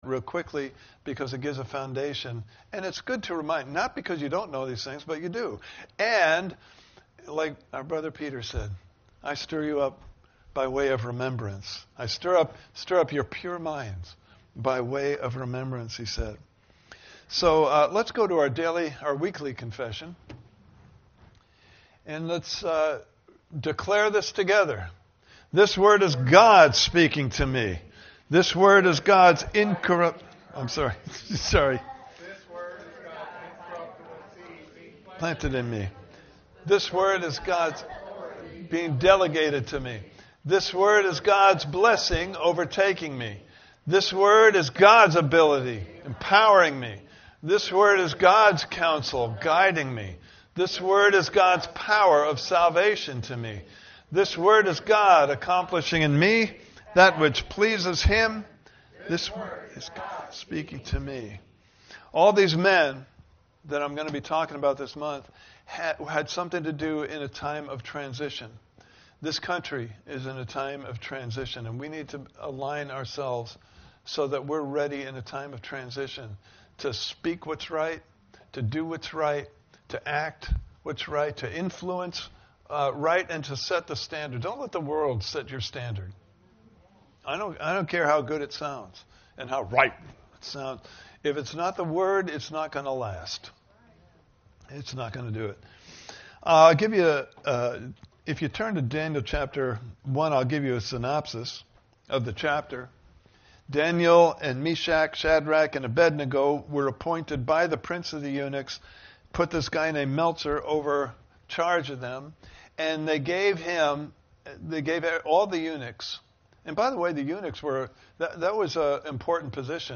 Series: Walking With the One Who Knows the Future Service Type: Sunday Morning Service « Part 5